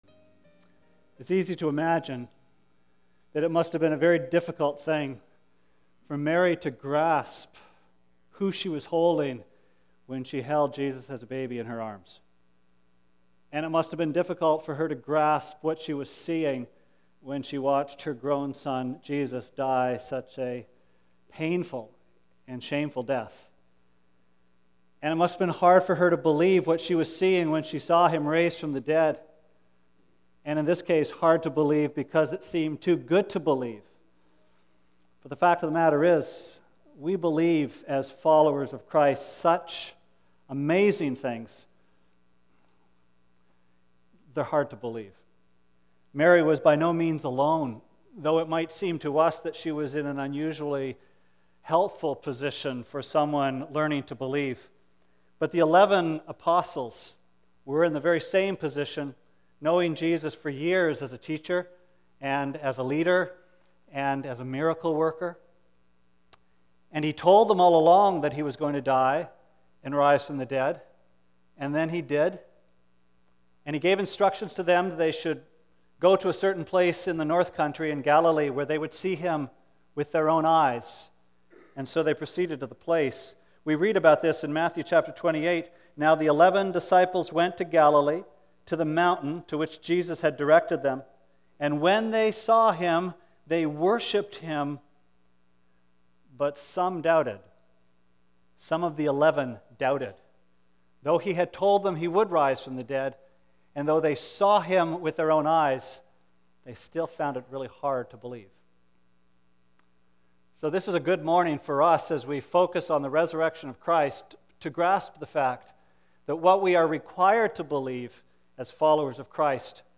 Sermon Archives - West London Alliance Church
April 24, 2011.EASTER SUNDAY MORNING